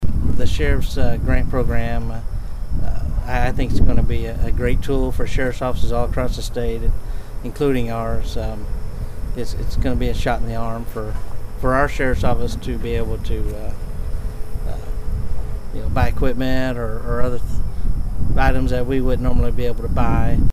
McClain is set to see his salary increase from $32,000 to $44,000 a year. McClain tells Bartlesville Radio that improvements to the Sheriff's Department are